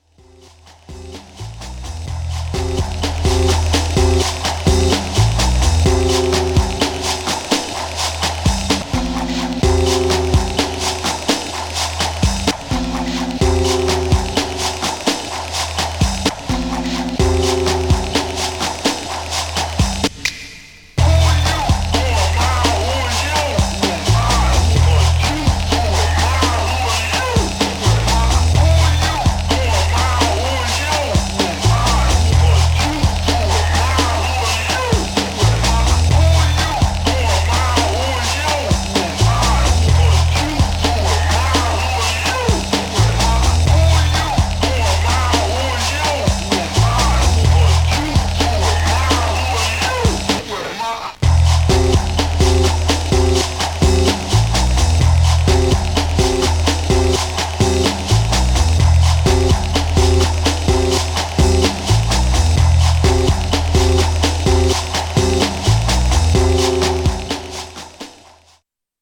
Styl: Drum'n'bass, Jungle/Ragga Jungle, Lounge